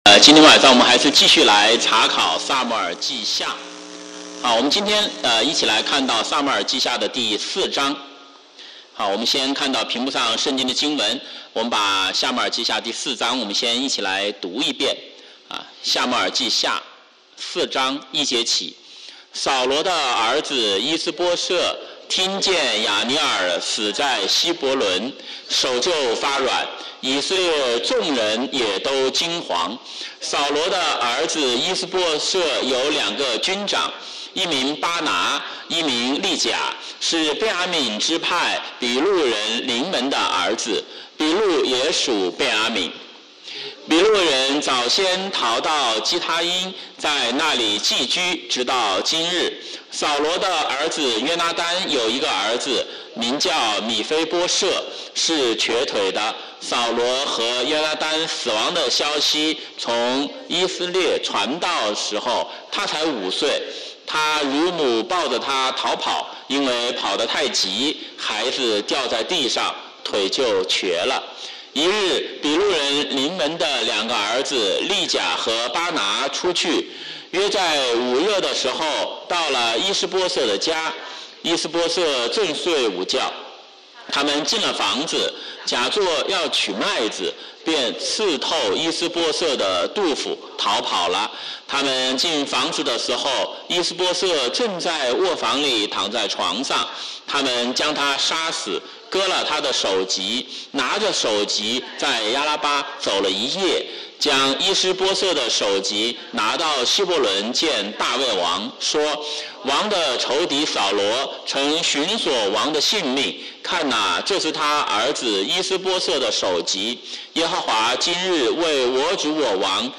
週五晚上查經講道錄音